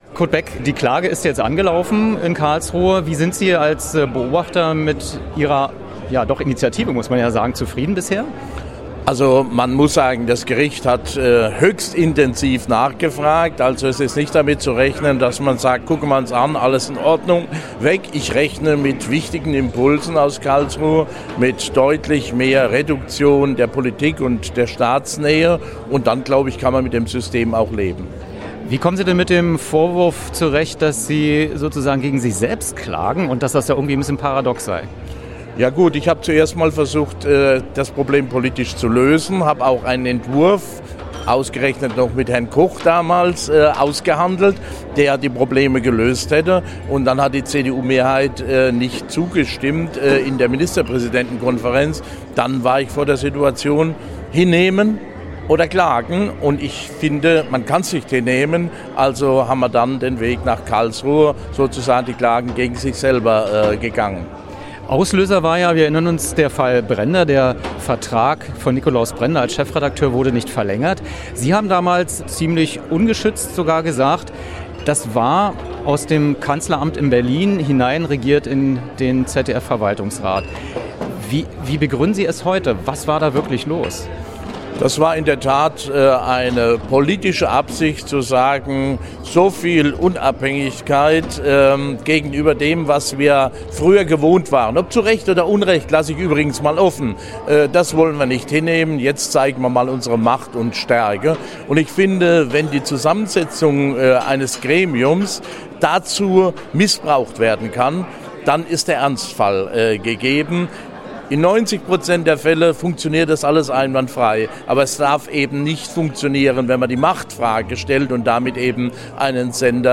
Wer: Kurt Beck, Vors. Friedrich-Ebert-Stiftung, Vors. des ZDF-Verwaltungsrates, ehem. MP Rheinland-Pfalz, SPD
Was: Interview zum Auftakt der Beratungen über die Normenkontrollklage zum ZDF-Staatsvertrag
Wo: Berlin, Hotel Pullmann Berlin, Schweizerhof, Budapester Straße 25